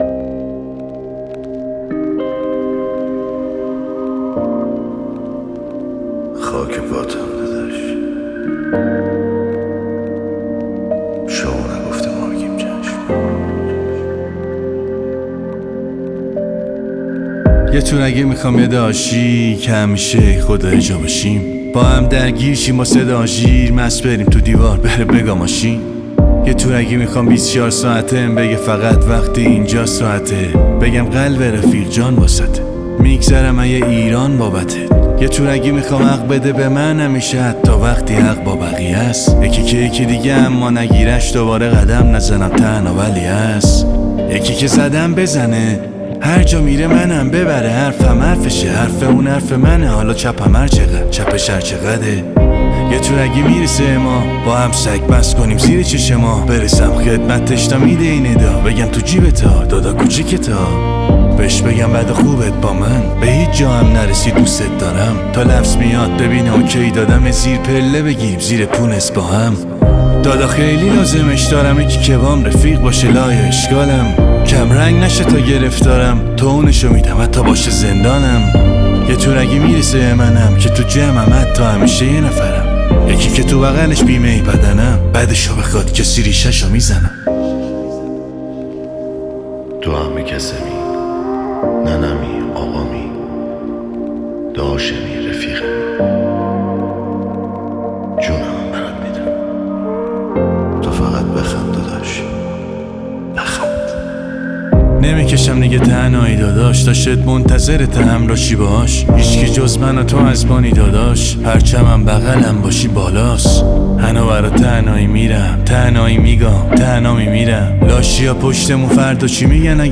تکست رپ
هیپ هاپ